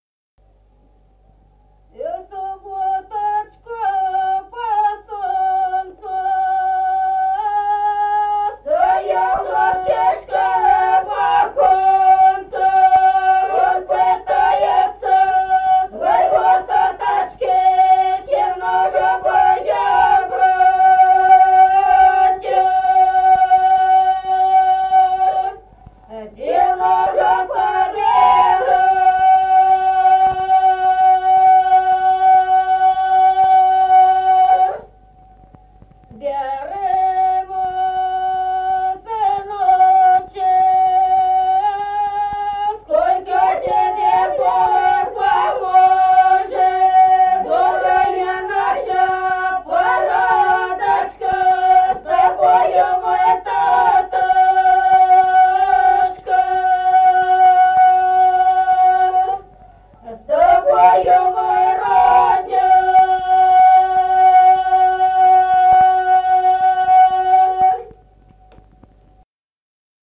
Народные песни Стародубского района «В субботочку по солнцу», свадебная.
1953 г., с. Остроглядово.